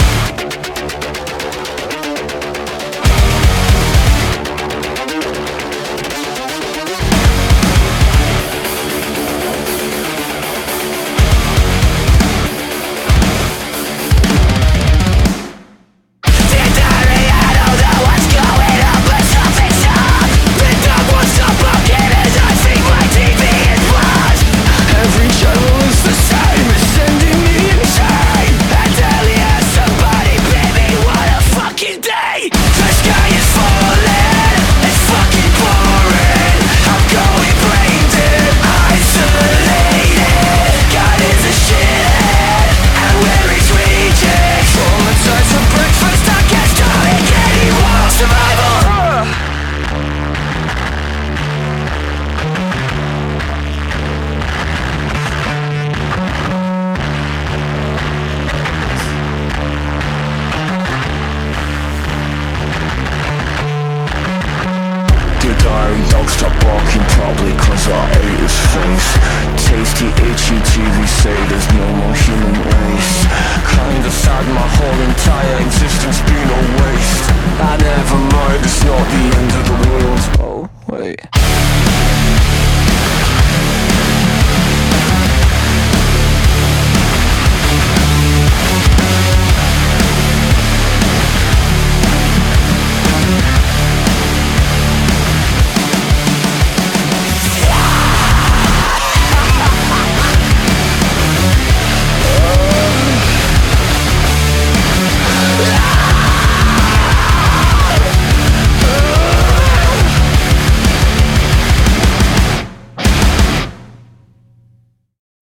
BPM118-236
Audio QualityCut From Video